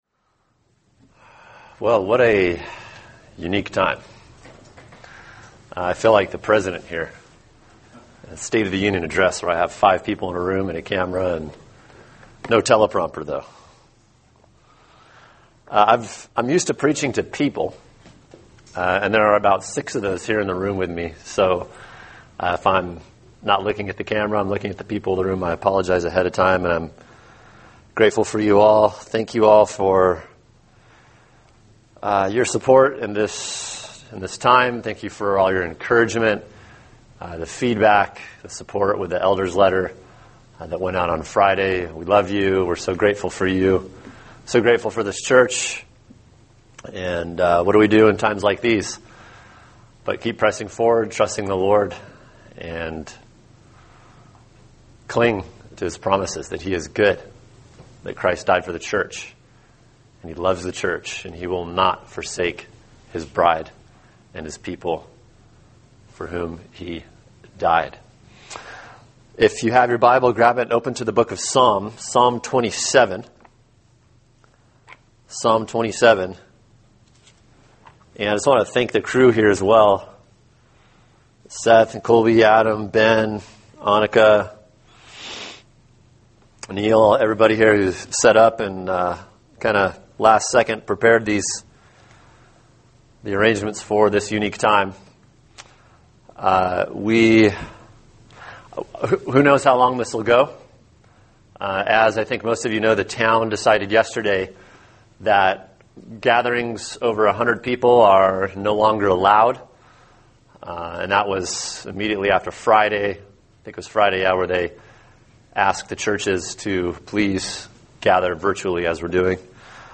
[sermon] Psalm 27 – The Greatness of God’s Comfort | Cornerstone Church - Jackson Hole